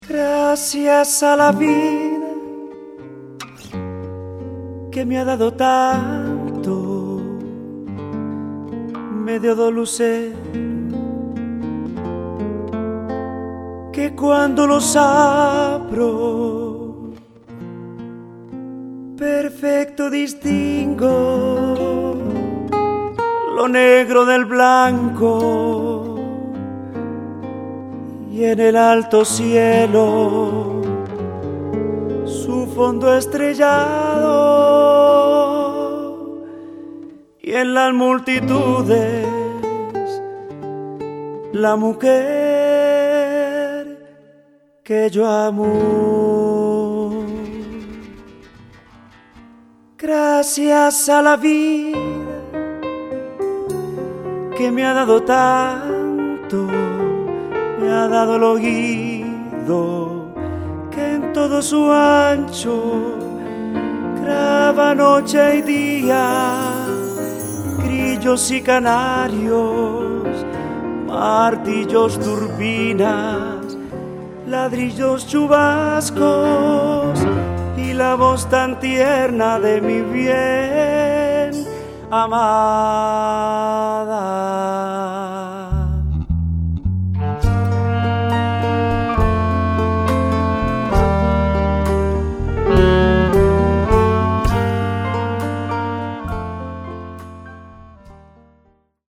orchestra di salsa, merengue, boleros, cumbia
voce e percussioni
piano
batteria e timbales
congas
tromba
sax tenore